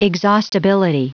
Prononciation du mot exhaustibility en anglais (fichier audio)
exhaustibility.wav